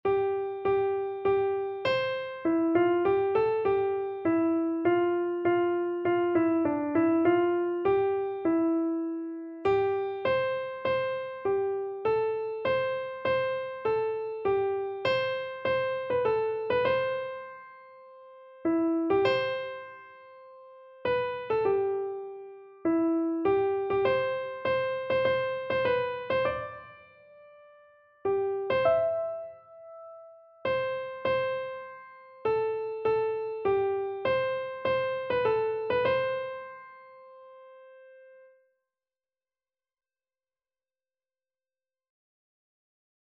Christian Christian Keyboard Sheet Music Wondrous Love
Free Sheet music for Keyboard (Melody and Chords)
C major (Sounding Pitch) (View more C major Music for Keyboard )
4/4 (View more 4/4 Music)
Keyboard  (View more Easy Keyboard Music)
Classical (View more Classical Keyboard Music)